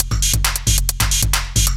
DS 135-BPM B4.wav